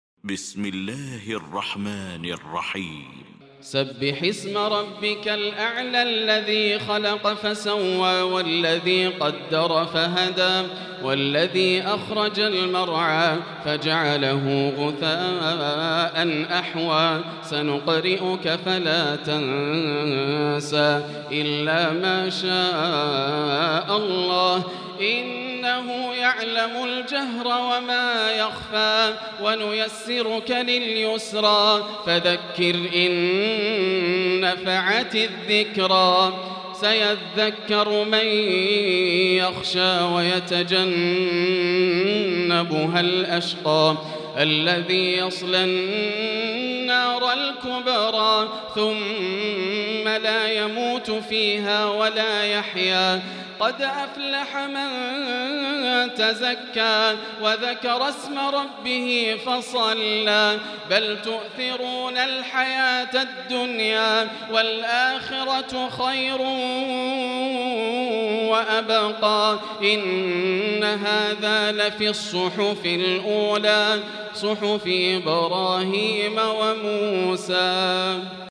المكان: المسجد الحرام الشيخ: فضيلة الشيخ ياسر الدوسري فضيلة الشيخ ياسر الدوسري الأعلى